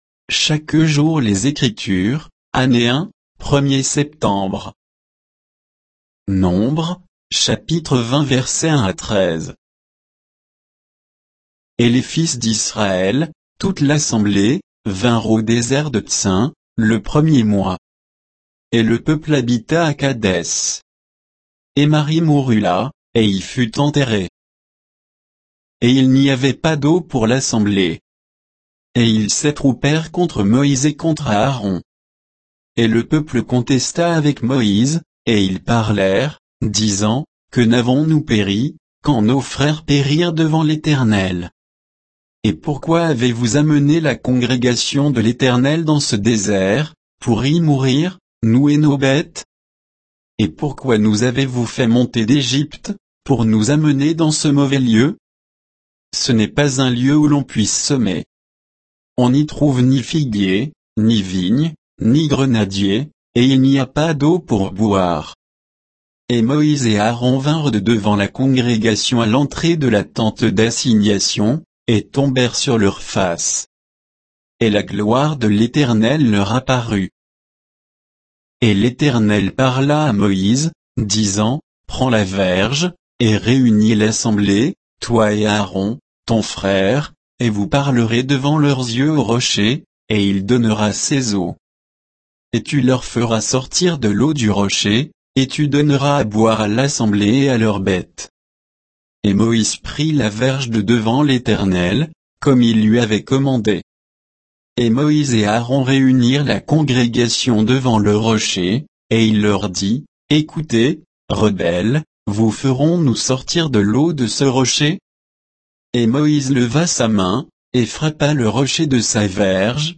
Méditation quoditienne de Chaque jour les Écritures sur Nombres 20